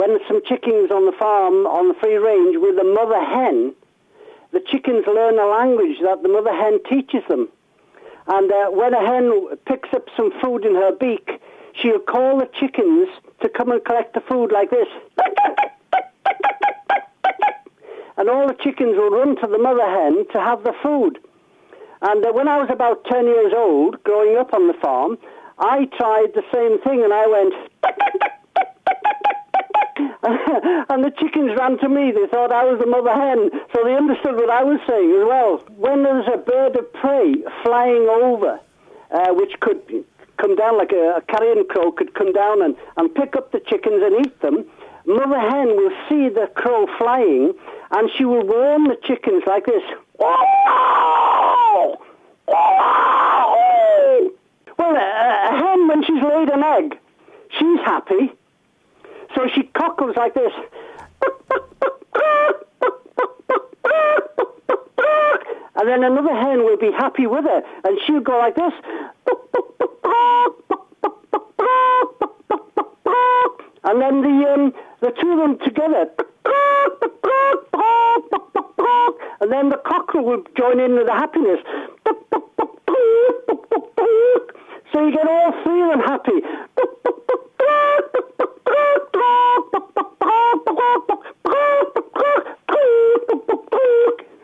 A man who impersonates chickens